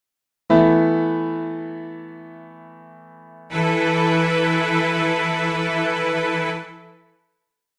It does not sound restful and asks to be resolved, which is done by including 3 instead of 4.
e_sus_chord.mp3